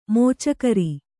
♪ mōcakari